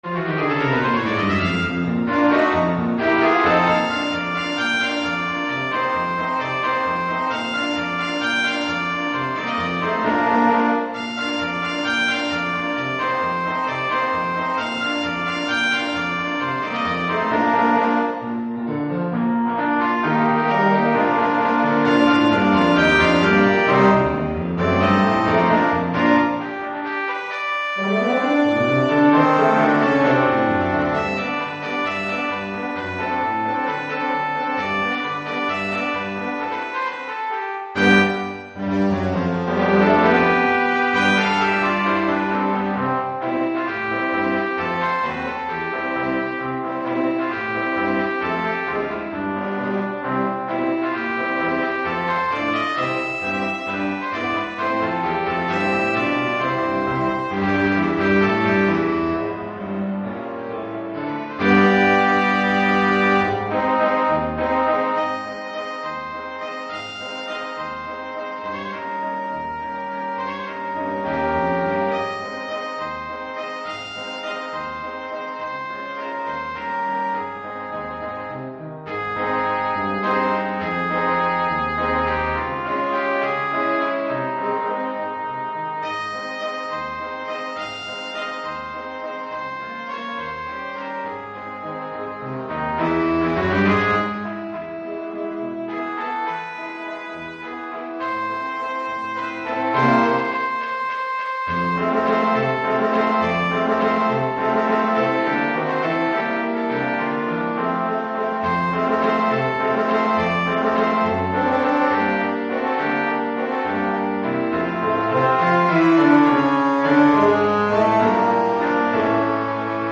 Keyboard & Drums optional.
für variables Quintett
Ensemble gemischt